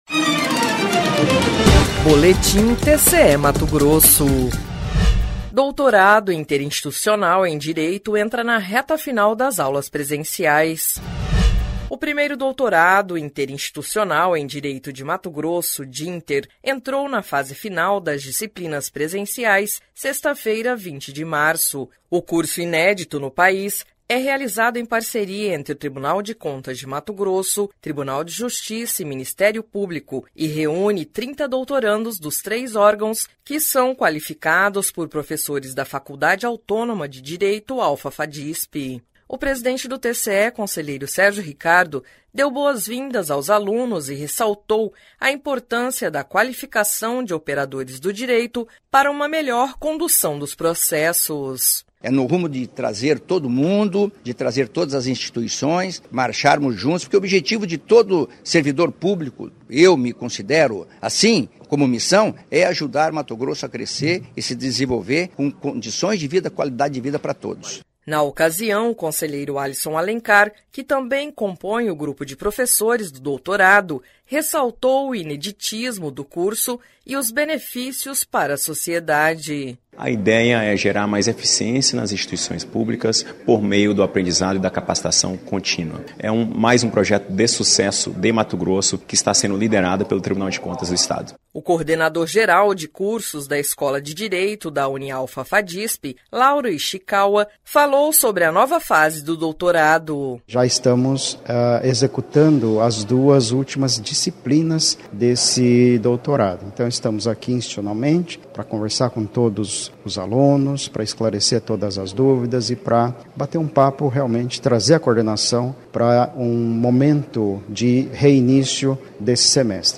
Sonora: Sérgio Ricardo – conselheiro-presidente do TCE-MT
Sonora: Alisson Alencar – conselheiro do TCE-MT